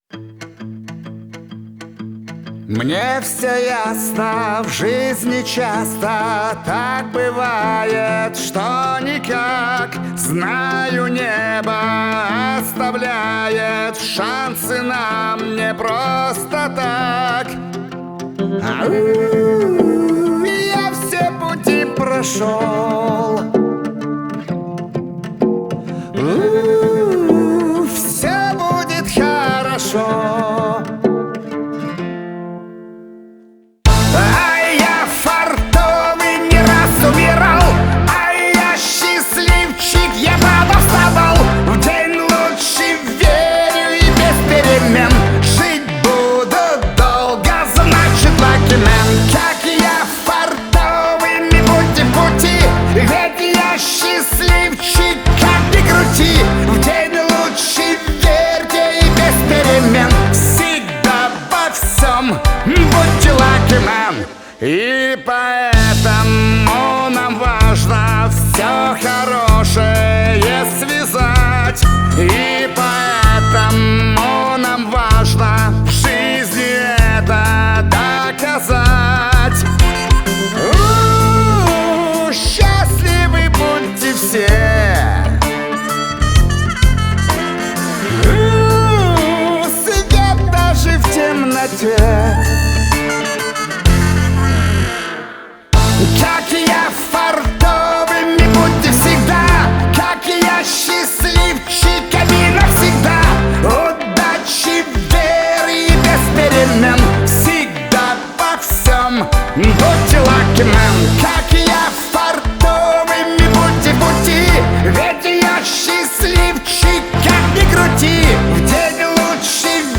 это проникновенная песня в жанре поп-рок